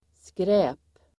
Uttal: [skrä:p]